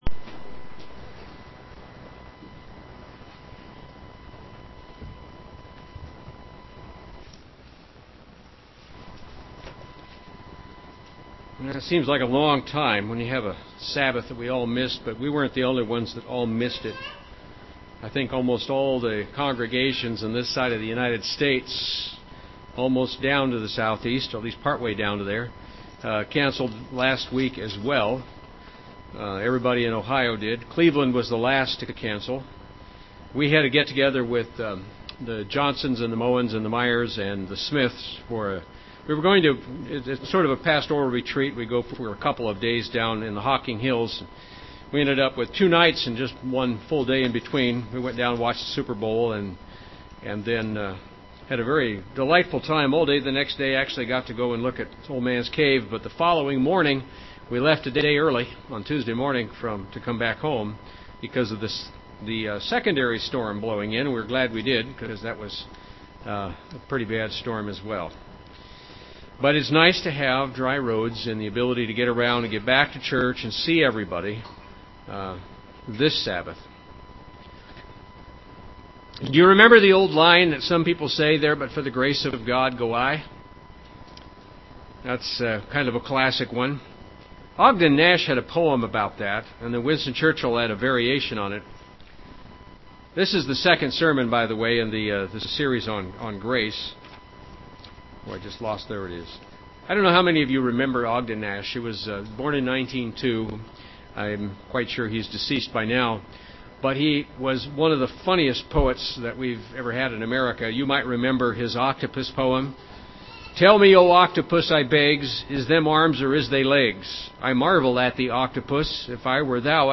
The Grace in Which We Stand, Part 2 UCG Sermon Studying the bible?